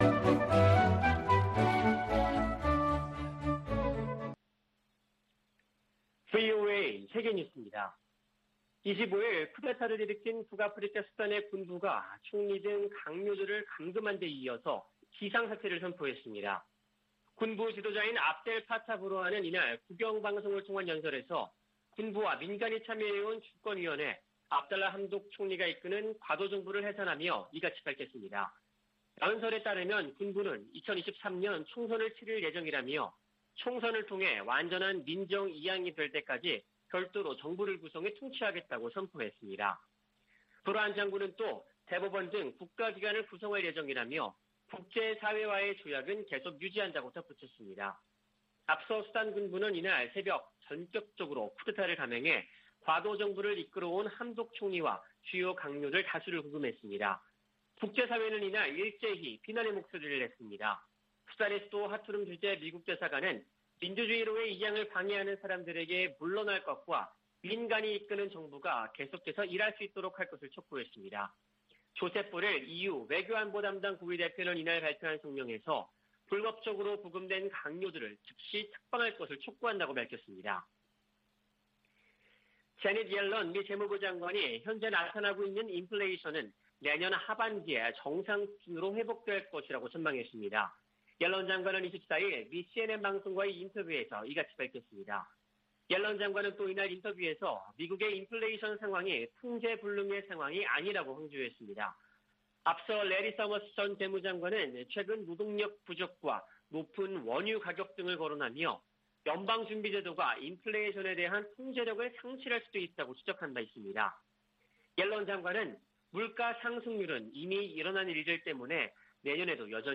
VOA 한국어 아침 뉴스 프로그램 '워싱턴 뉴스 광장' 2021년 10월 26일 방송입니다. 성 김 미 대북특별대표는 한반도 종전선언 등 관여 방안을 계속 모색하겠다면서도 북한의 탄도미사일 발사를 도발이라고 비판했습니다. 제76차 유엔총회에 북한 핵과 탄도미사일 관련 내용이 포함된 결의안 3건이 발의됐습니다. 북한이 플루토늄 추출과 우라늄 농축 등 핵 활동을 활발히 벌이고 있다는 우려가 이어지고 있습니다.